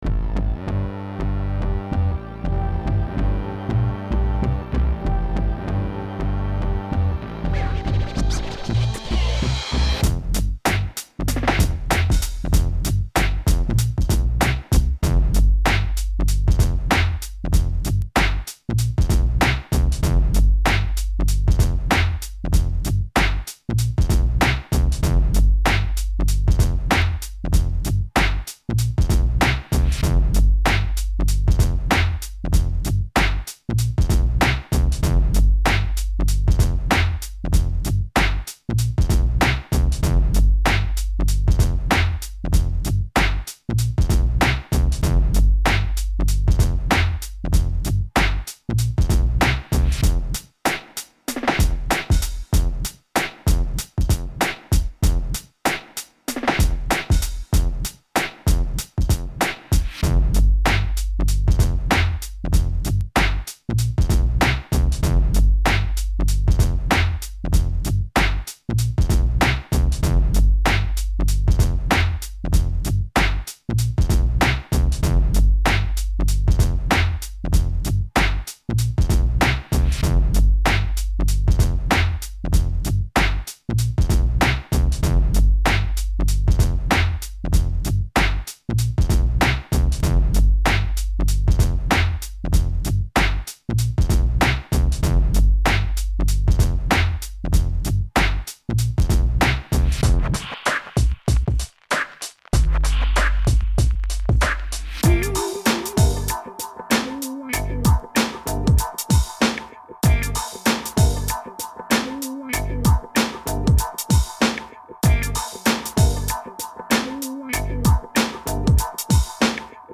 NEW INSTUMENTAL (haven't finished lyrics) thanks for checking out our website!! ballers 4 life!